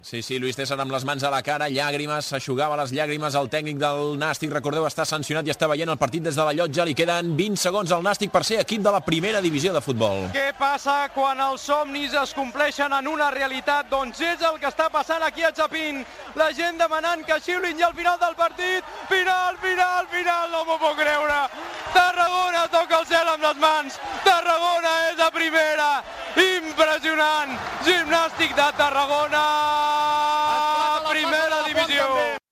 L'equip de futbol mascúlí del Nàstic de Tarragona empata amb el Xerez al camp de Chapín i aconsegueix l'ascens a primera divisió. Narració dels últims segons del partit.
Esportiu